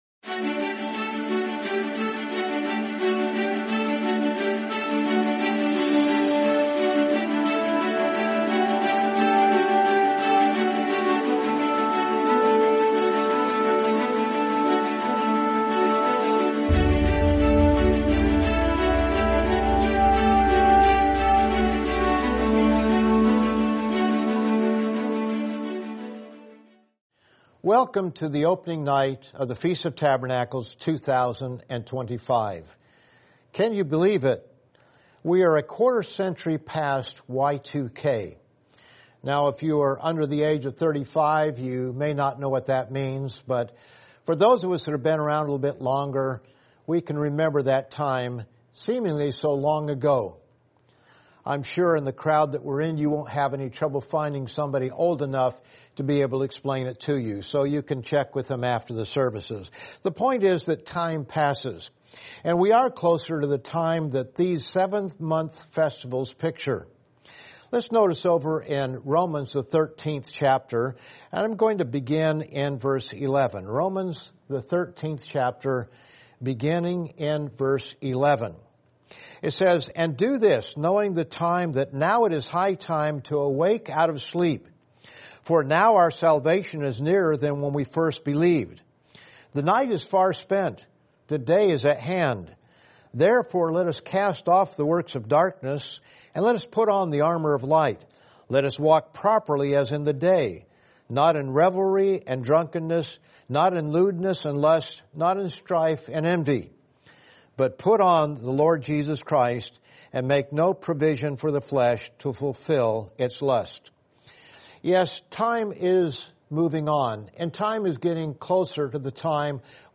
Feast of Tabernacles 2025: Give a Good Feast | Sermon | LCG Members